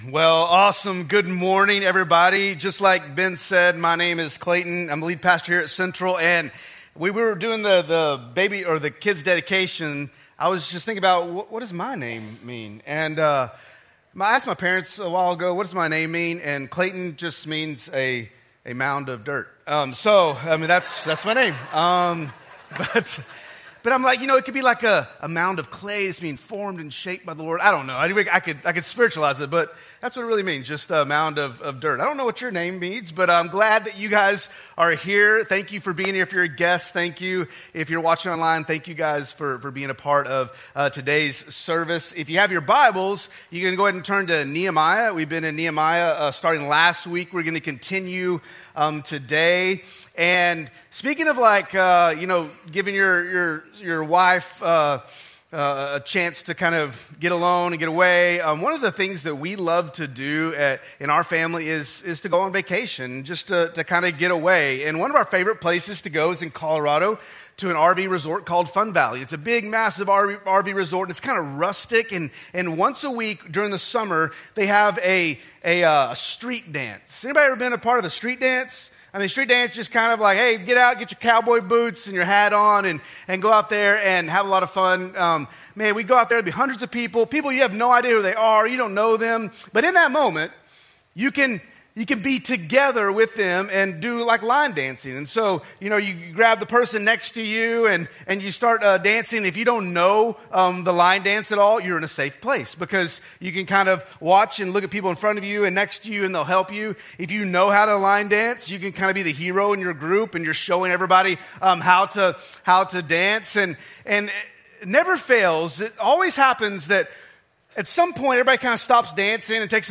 A message from the series "RESOUL."